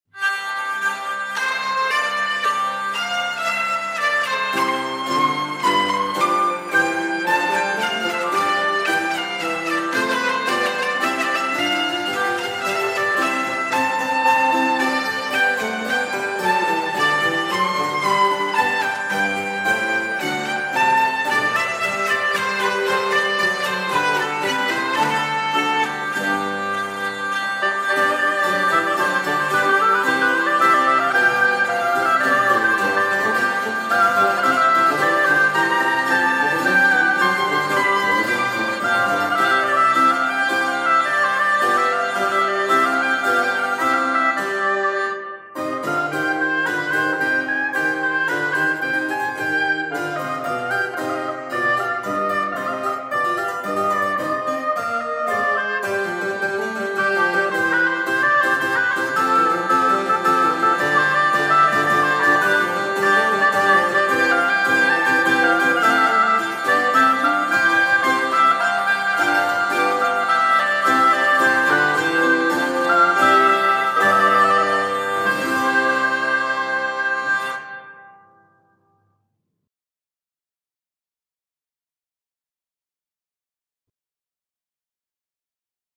502BarocophileTr14VivaldiHurdyGurdy.mp3